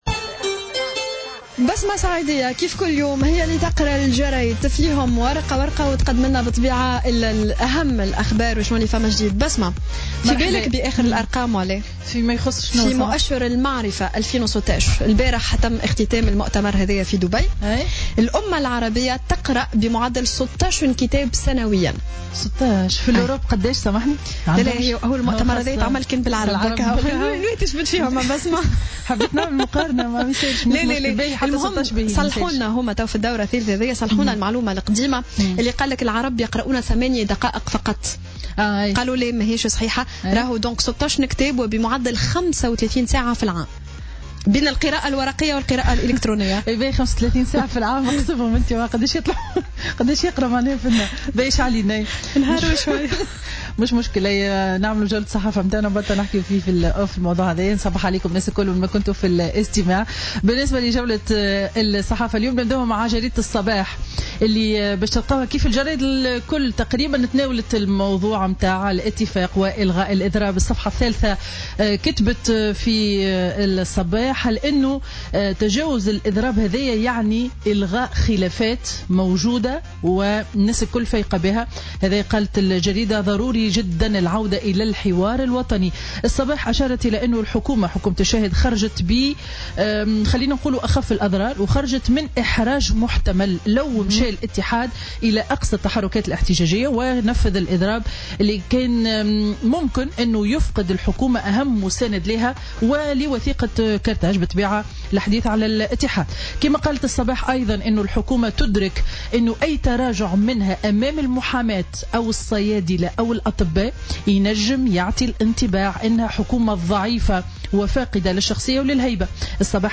Revue de presse du jeudi 8 décembre 2016